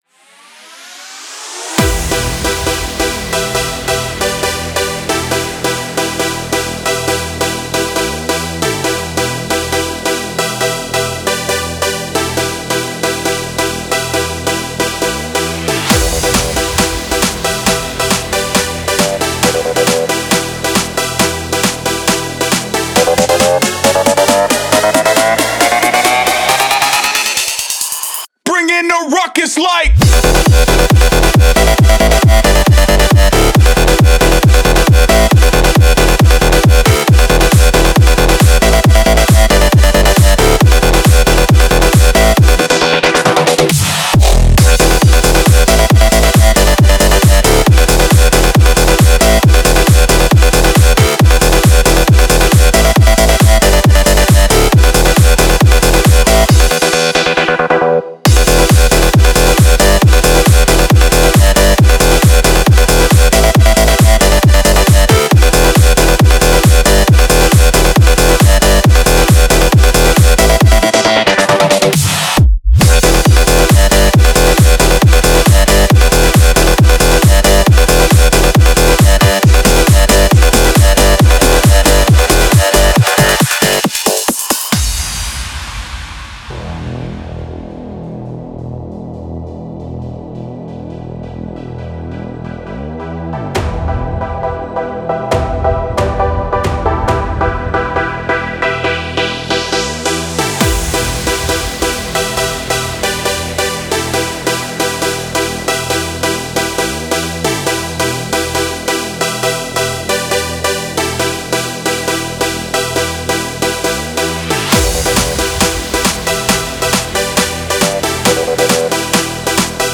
Hands Up song